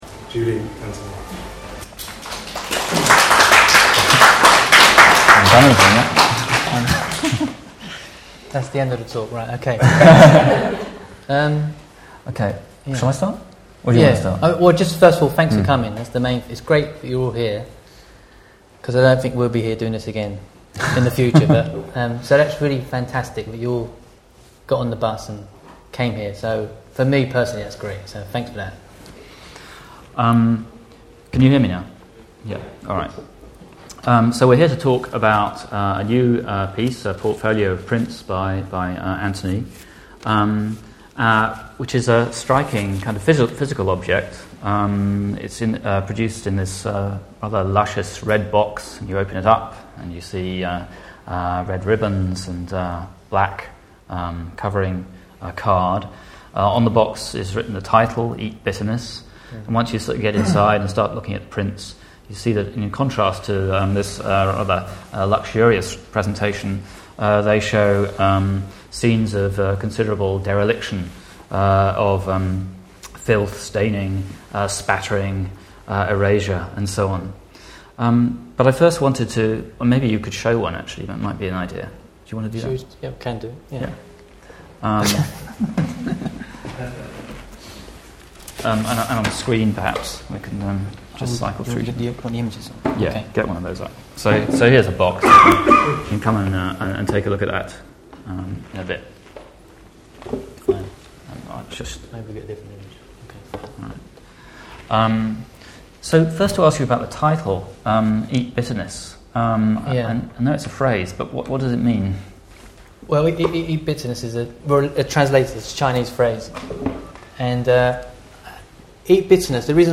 eat bitterness was publicly launched at The Photographers' Gallery , London in March 2007
Listen to the conversation here (MP3 file size 28.5mb / conversation with Q & A : 47 minutes)